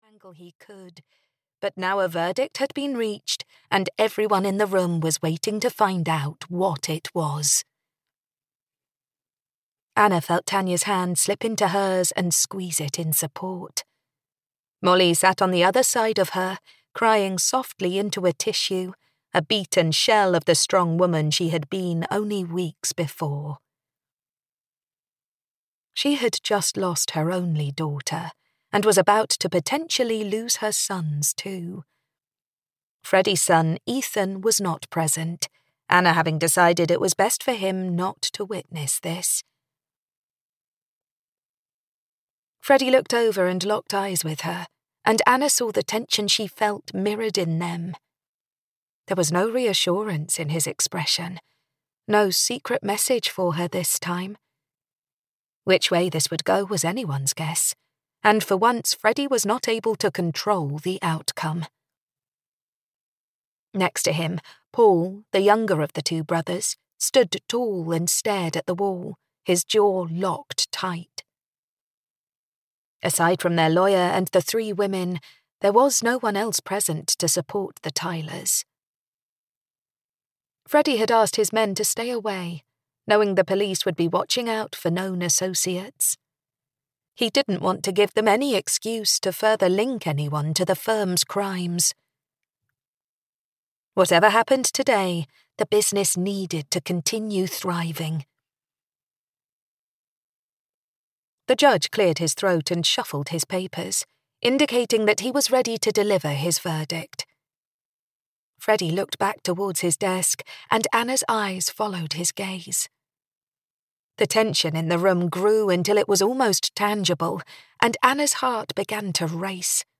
Fearless Girl (EN) audiokniha
Ukázka z knihy